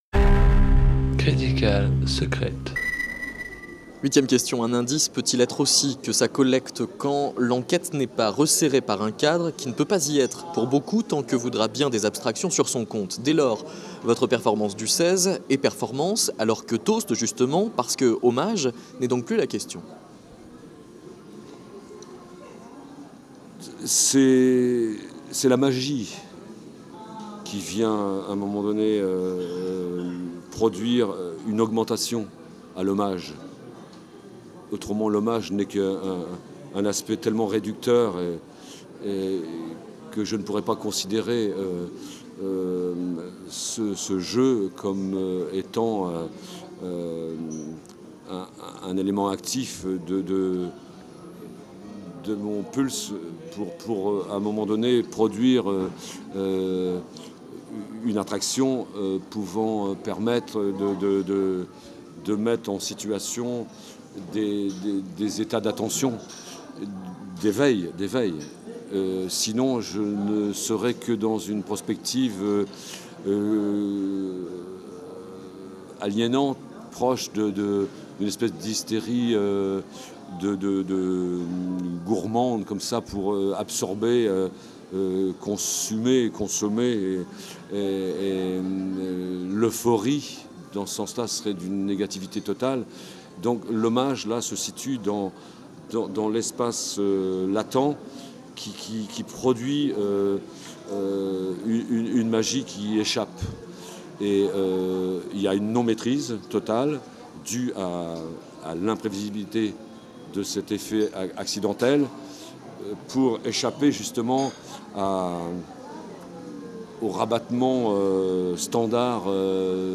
Intervenant: la conductrice du métro Sonographie, série. 5/8.